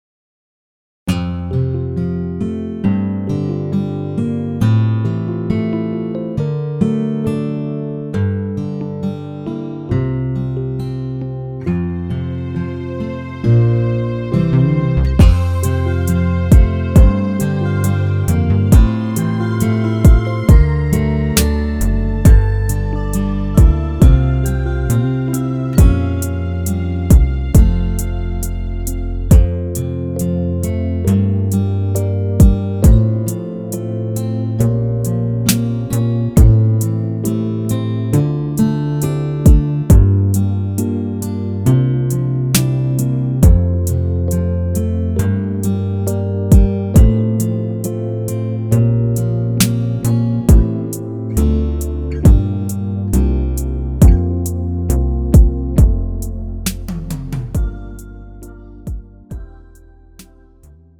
음정 (-2)
장르 가요 구분 Lite MR
Lite MR은 저렴한 가격에 간단한 연습이나 취미용으로 활용할 수 있는 가벼운 반주입니다.